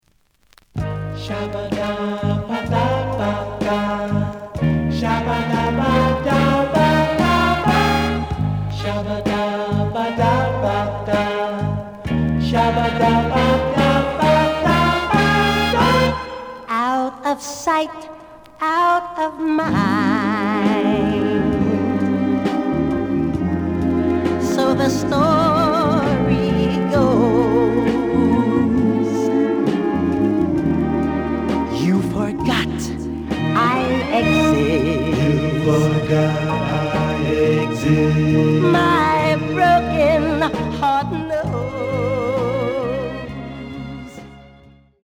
The audio sample is recorded from the actual item.
●Genre: Soul, 60's Soul
Slight affect sound.